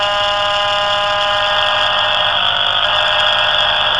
2.000 АМ в серверной (хабы/бесперебойники жужжат)
servernaya.wav